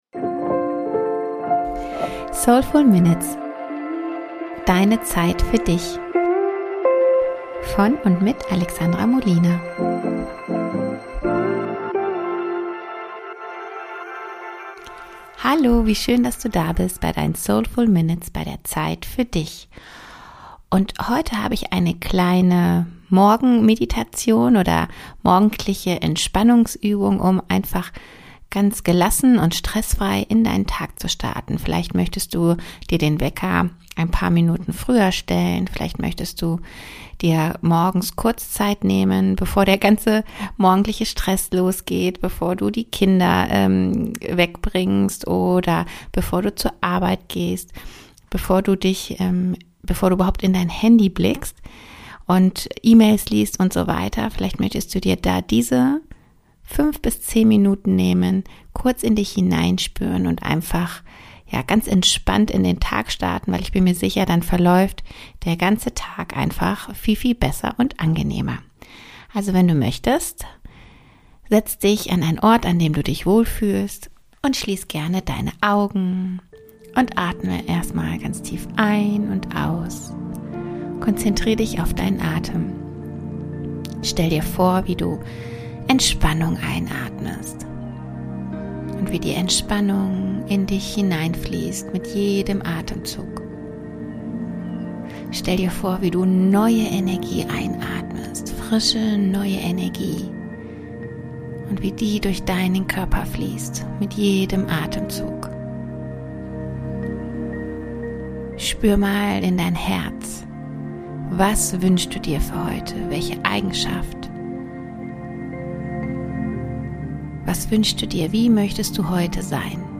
Gelassen in den Tag starten – eine kurze Morgenmeditation